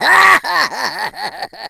21:28, January 26, 2021 King Boo Laugh.oga (file)
King_Boo_Laugh.oga